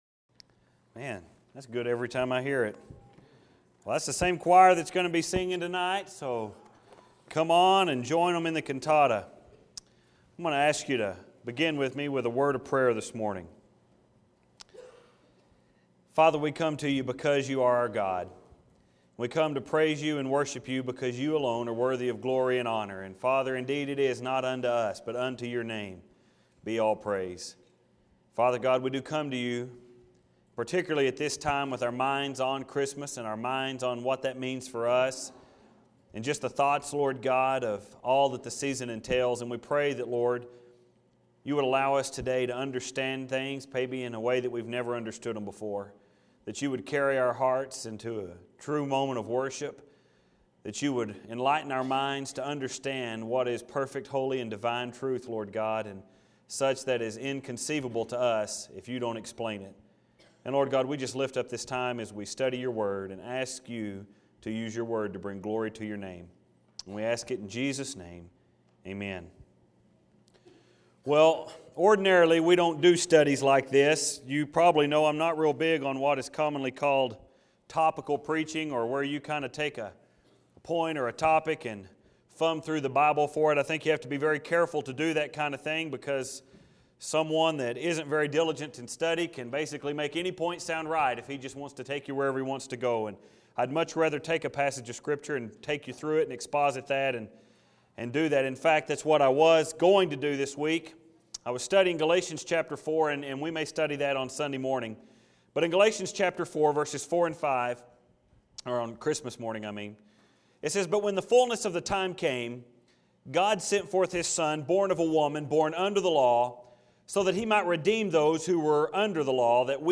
I don’t usually preach sermons like this one.